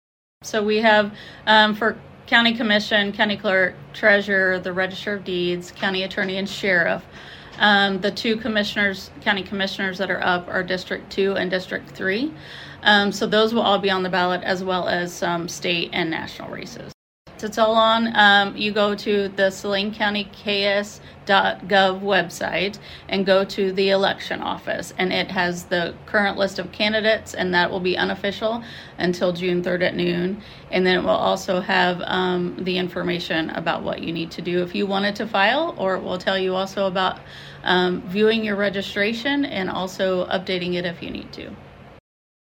Saline County Clerk Jamie Doss tells KSAL News that normally the deadline to file to run for office is June 1st.